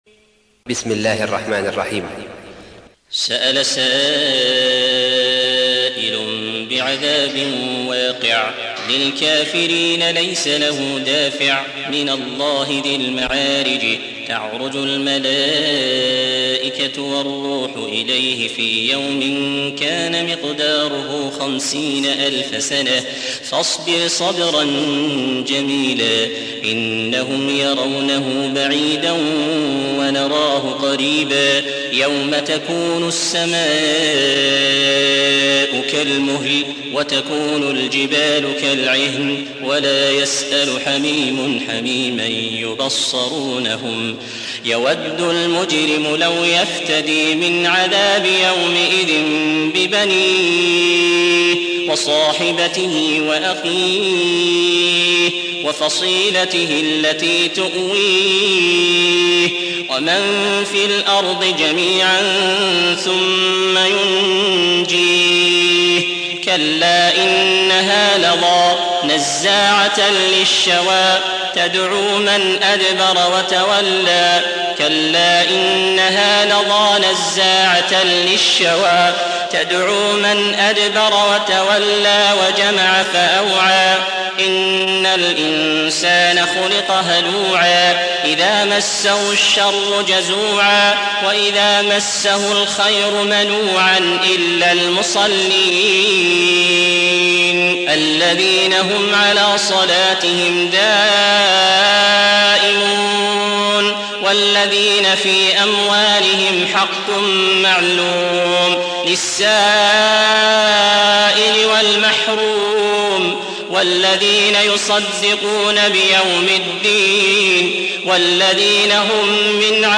70. سورة المعارج / القارئ